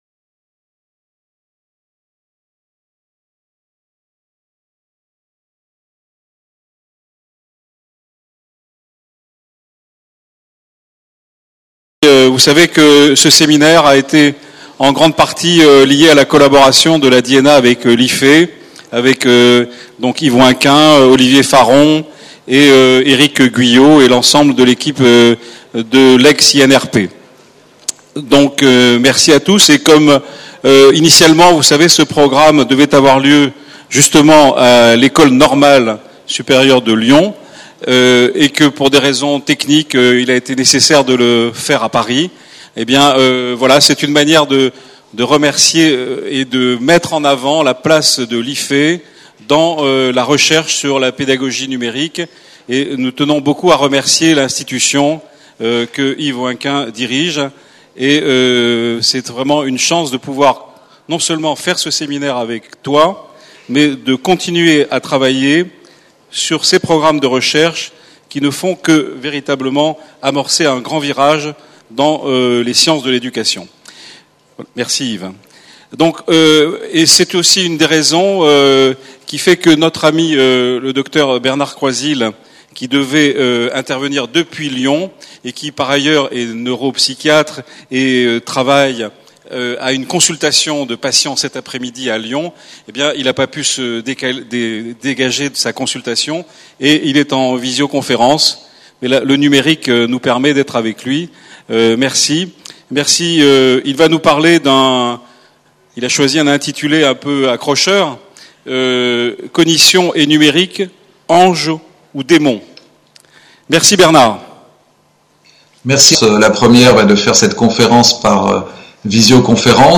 PREMIER SÉMINAIRE INTERNATIONAL SANKORÉ DE RECHERCHE UNIVERSITAIRE SUR LA PÉDAGOGIE NUMÉRIQUE Conférence-Débat : INSERM / SANKORE : ZOOM SUR LES NEUROSCIENCESQue disent les neurosciences sur l’Education numérique ?